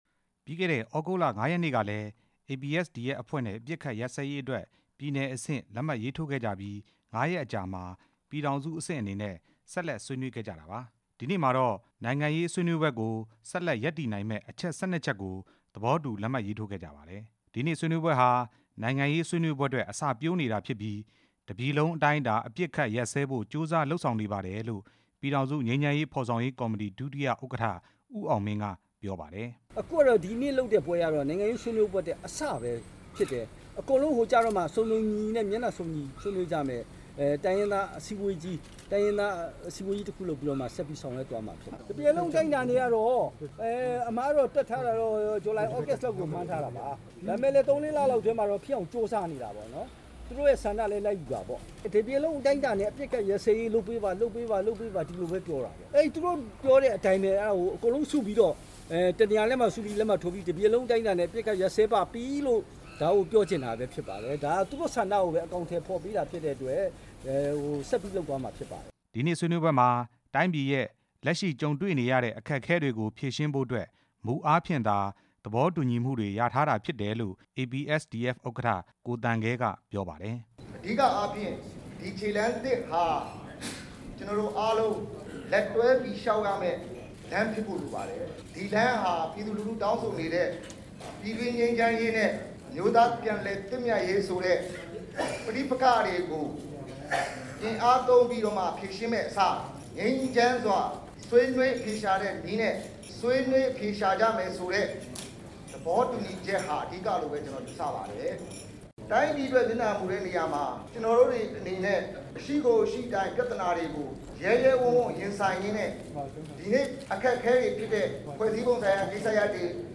ပြည်ထောင်စုအဆင့် လက်မှတ်ရေးထိုးပွဲအကြောင်း တင်ပြချက်